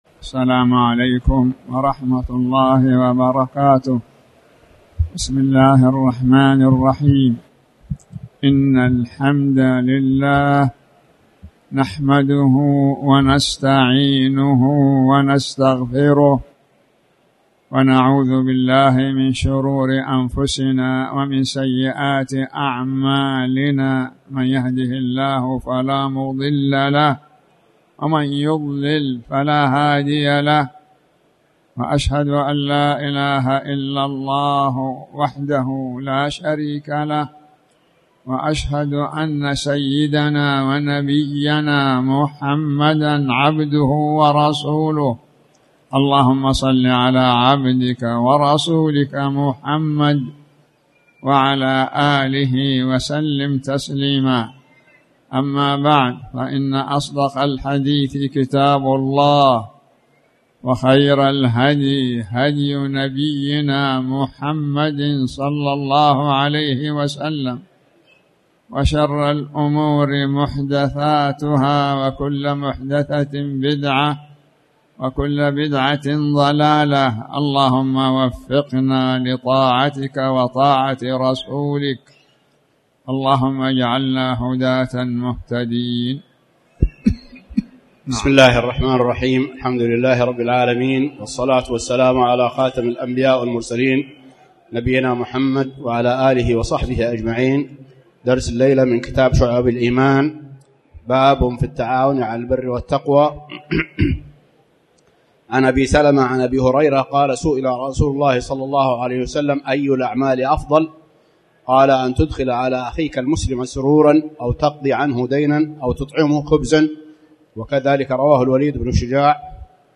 تاريخ النشر ٢٧ محرم ١٤٣٩ هـ المكان: المسجد الحرام الشيخ